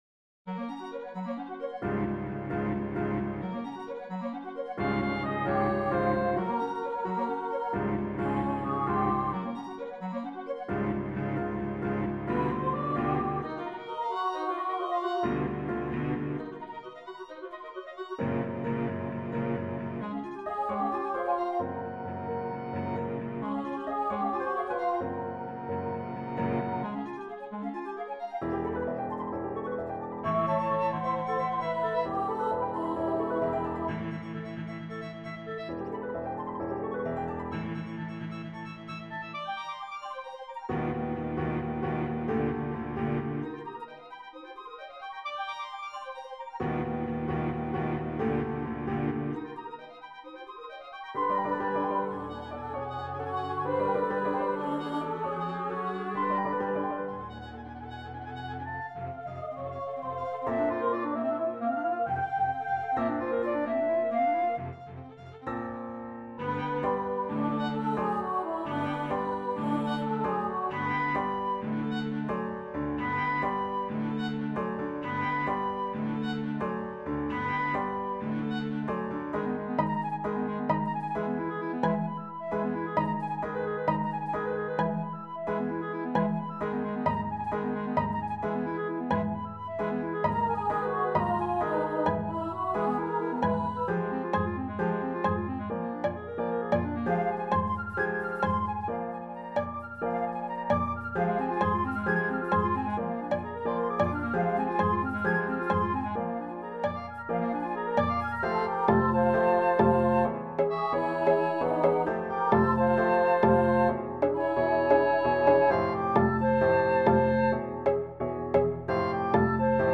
ENS.2016.4 | 37′ Poems by Michael Robbins Version A: Soprano, Flute, Clarinet, Piano, Violin Cello Version B: Soprano or Tenor, Piano, String Quartet
Midi-7.-Big-Country.mp3